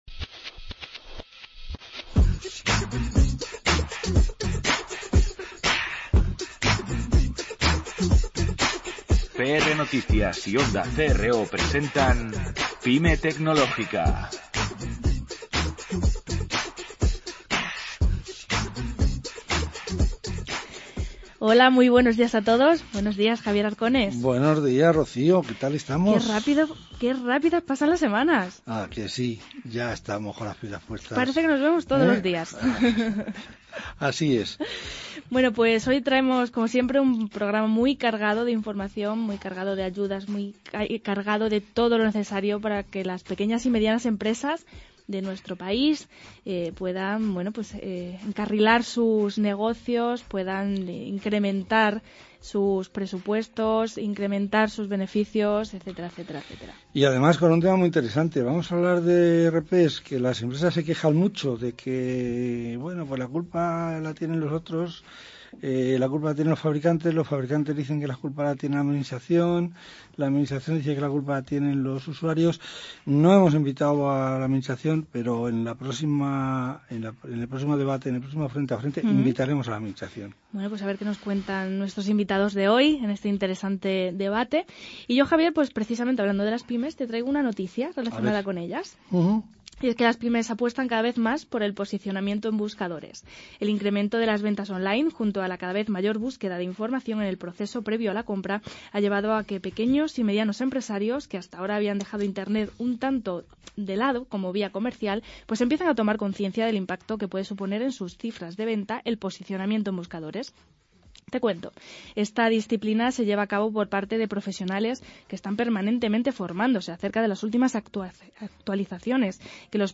Para hablar de estos temas, en esta ocasión contamos con la presencia en nuestros estudios de:
El debate comenzó poniendo sobre la mesa, primero, que es la normativa SEPA.